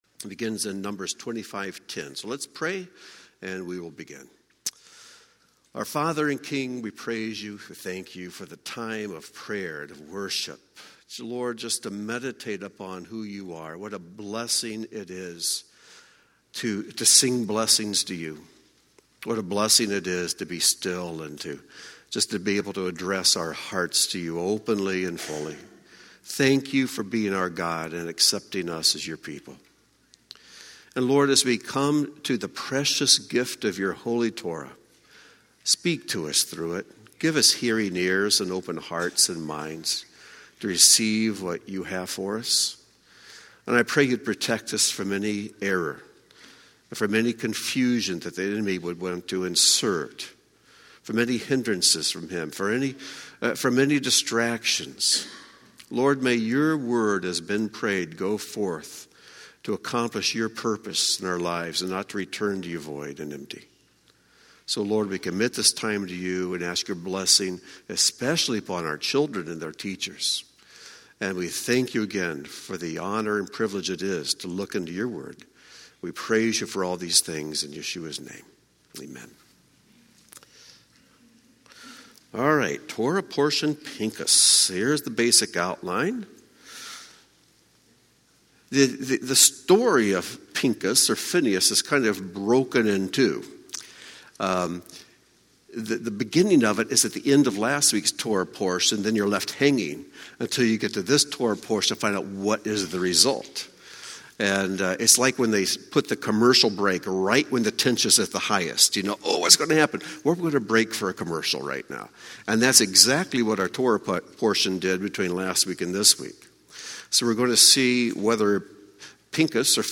Join Beth Tikkun for a teaching from portion Pinchas centered on brokenness.